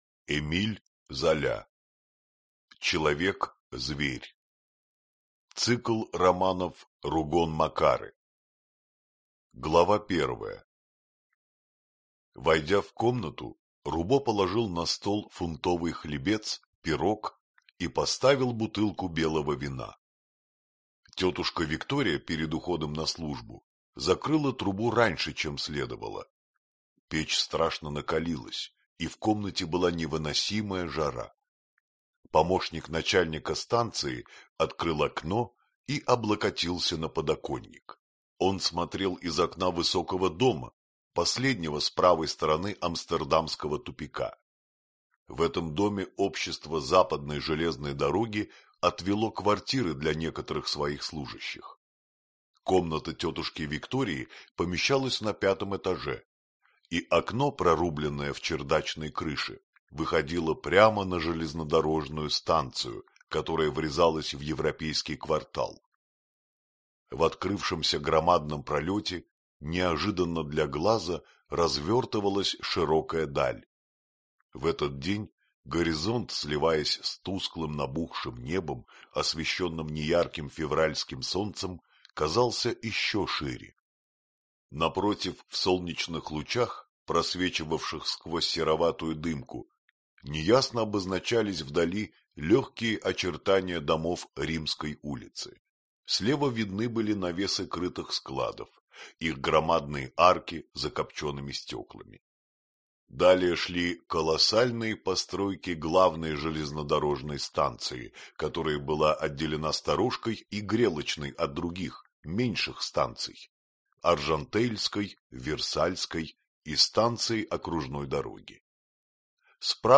Аудиокнига Человек-Зверь | Библиотека аудиокниг
Прослушать и бесплатно скачать фрагмент аудиокниги